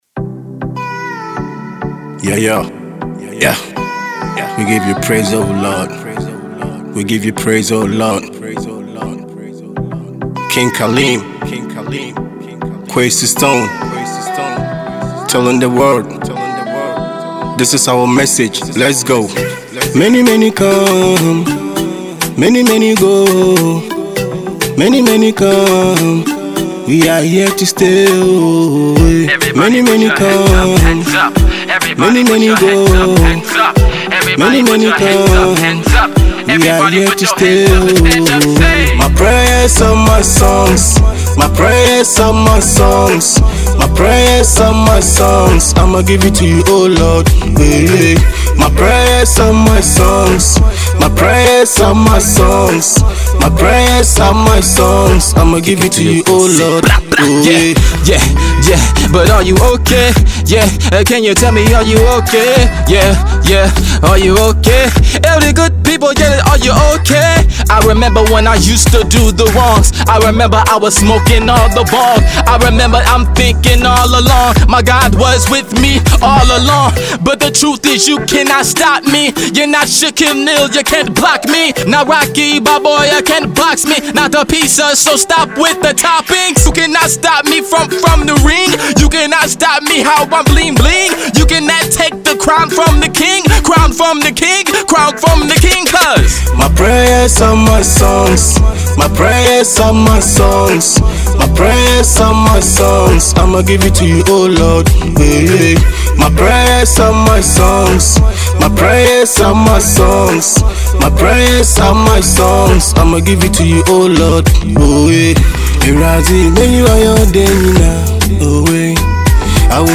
inspirational track